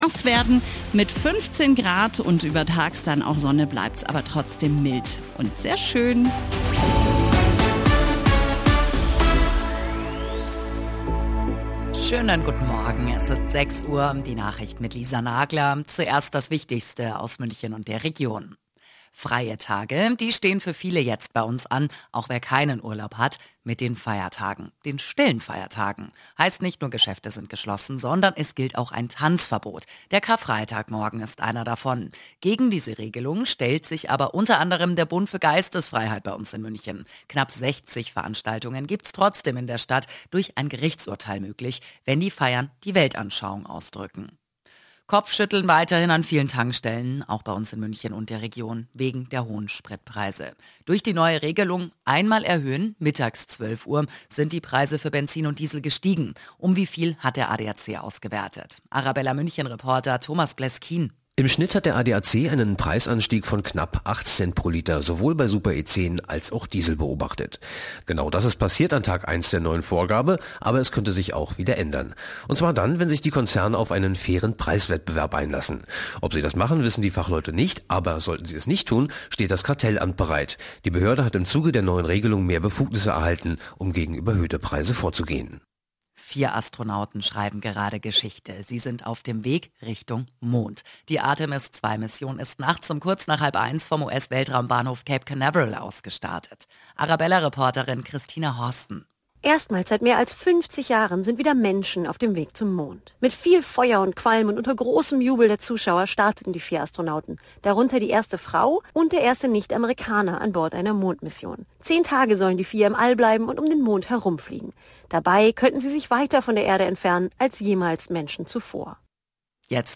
Nachrichten , Nachrichten & Politik
Die aktuellen Nachrichten zum Nachhören